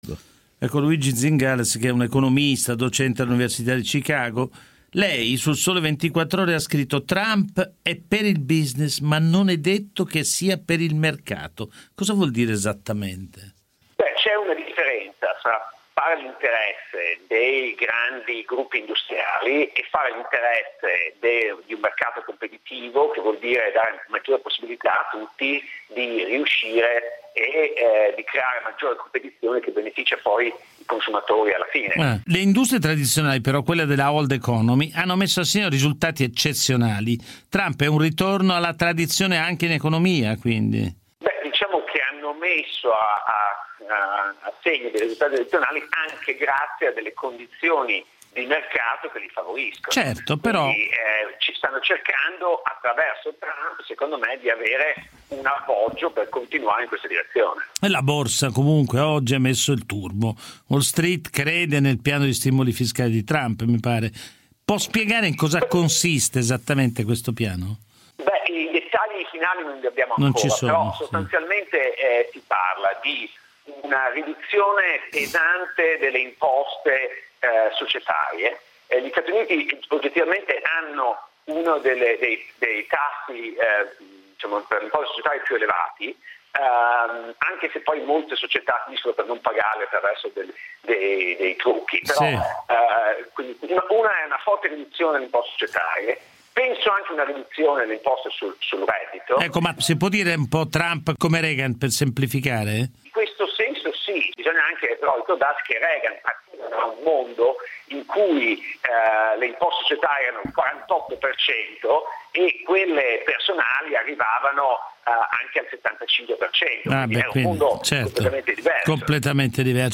Cosa cambia per l’Europa con la presidenza Trump? Ne ho parlato con Giovanni Minoli a “Mix 24“.
Qui di seguito il podcast della conversazione (durata 8min12sec):